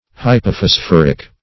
hypophosphoric - definition of hypophosphoric - synonyms, pronunciation, spelling from Free Dictionary
Hypophosphoric \Hy`po*phos*phor"ic\, a. [Pref. hypo- +